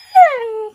wolf_whine.ogg